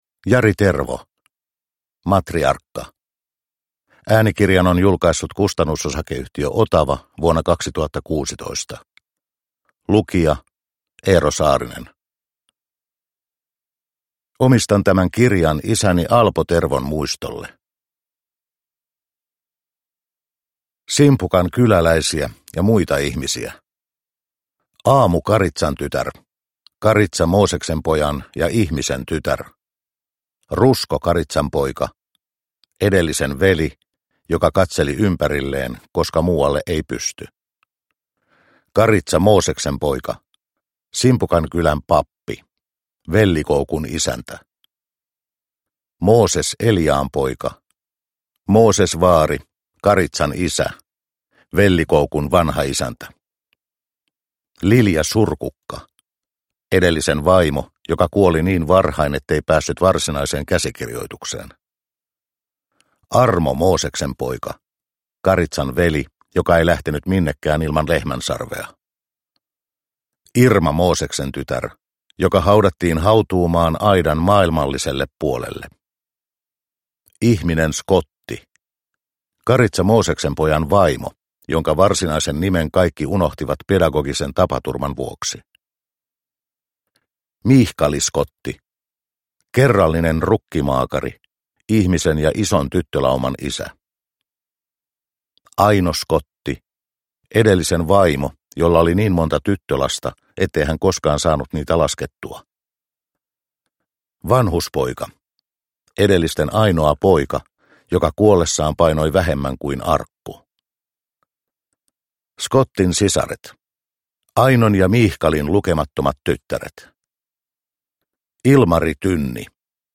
Matriarkka – Ljudbok – Laddas ner